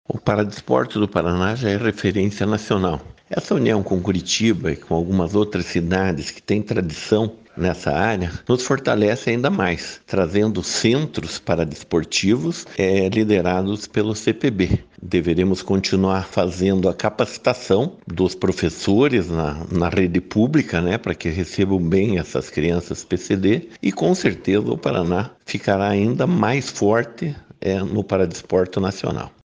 Sonora do secretário do Esporte, Helio Wirbiski, sobre a parceria entre Estado, UFPR e Comitê Paralímpico Brasileiro para expansão de atividades do paradesporto